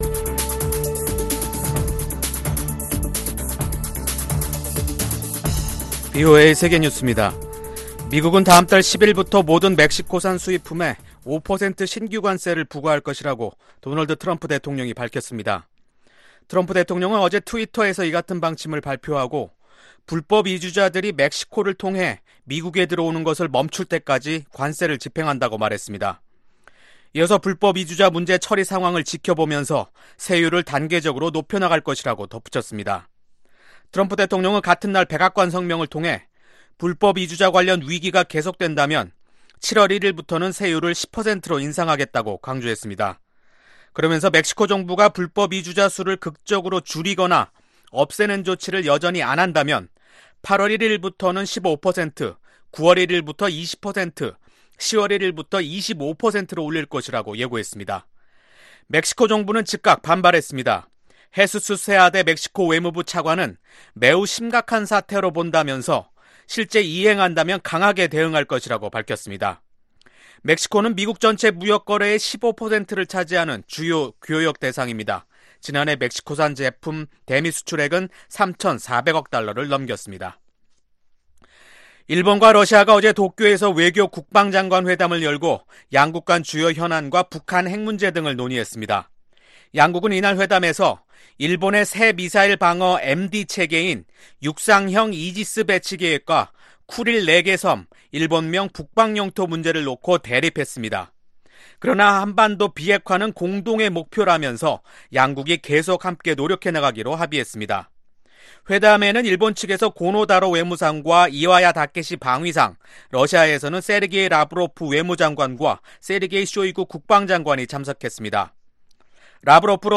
VOA 한국어 간판 뉴스 프로그램 '뉴스 투데이', 2019년 5월 31일 2부 방송입니다. 존 볼튼 백악관 국가안보보좌관은 ‘트럼프 대통령이 이란이든 북한이든 운반 가능한 핵무기를 갖지 못하게 해야 한다는데 매우 단호하다’고 말했습니다. 고조되는 미-중 간 무역갈등이 북한 문제 해결에 걸림돌이 되지는 않을 것이라는 전망이 나왔습니다.